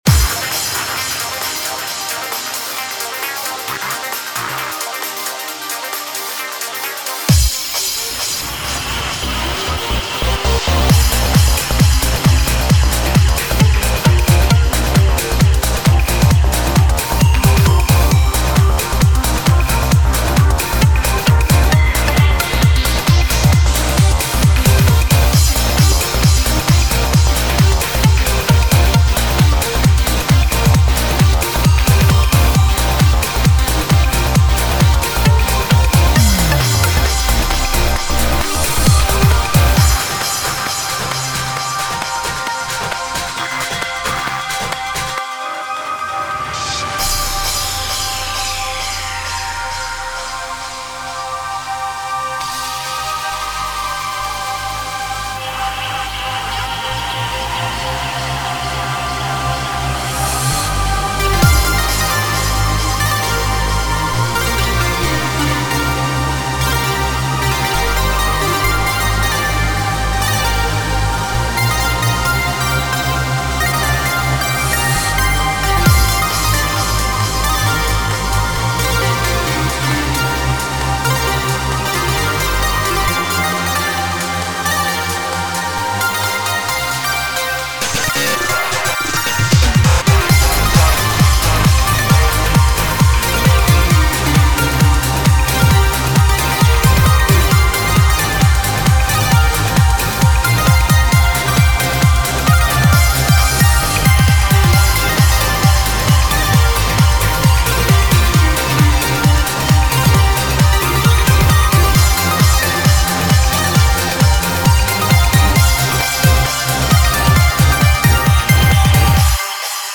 BPM67-133
Audio QualityMusic Cut